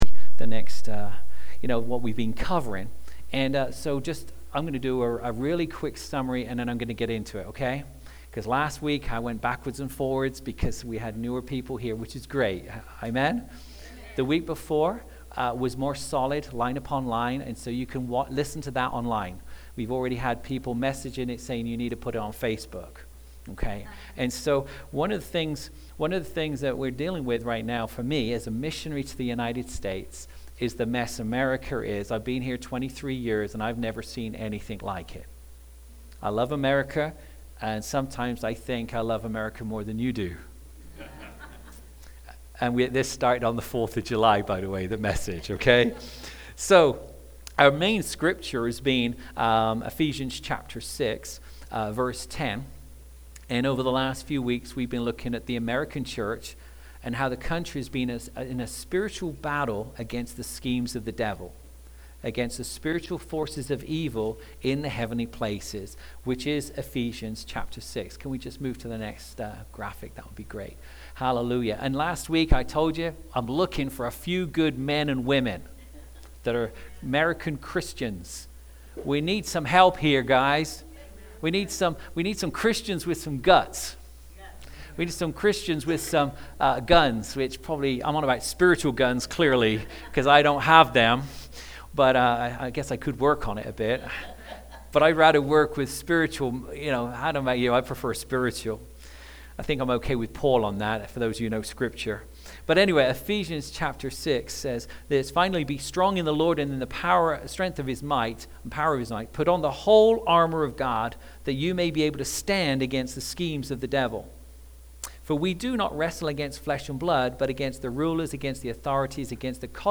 Classic Sermons-Library Make the American Church Great Again!